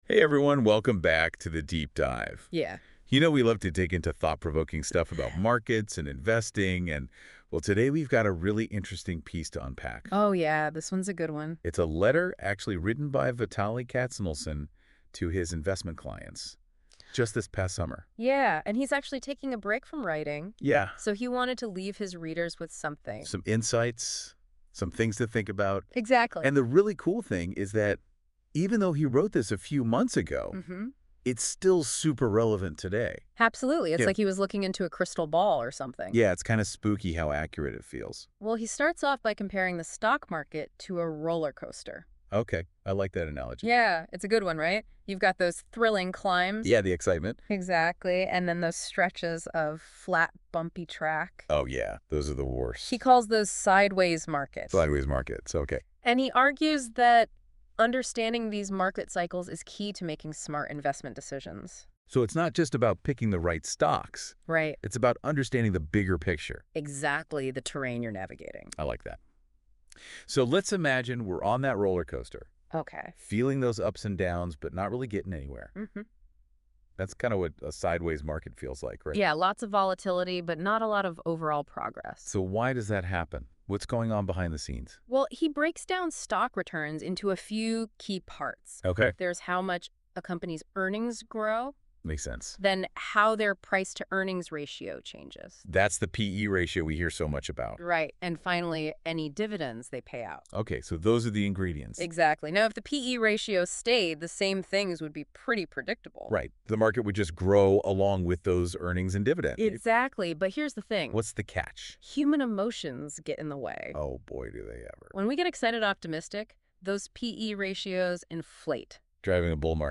I asked AI to transform my essays into a radio show-style conversation. In this episode, topic is stock market math, sideways markets, the role of P/E in market cycles, impact of interest rates on P/E, economic analysis, Magnificent Seven stocks, NVIDIA, and a lot more.